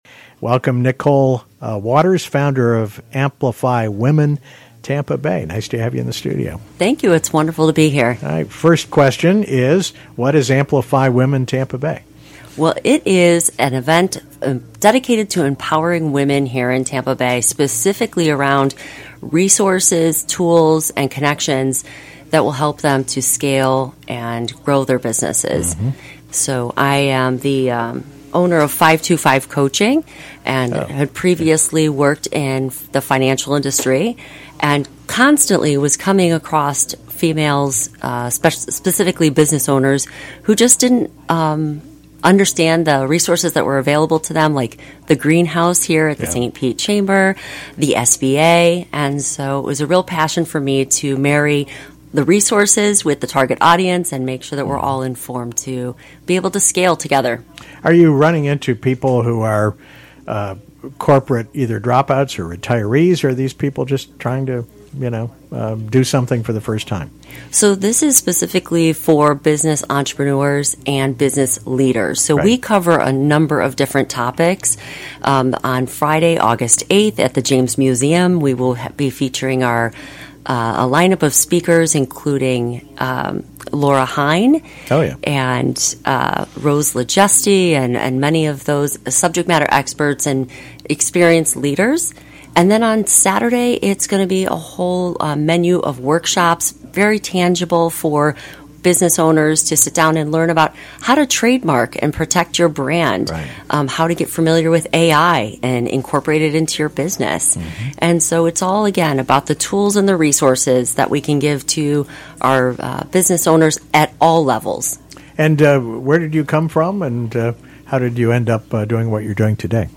Interview
On 3/5 during RadioStPete Day at Thrive DTSP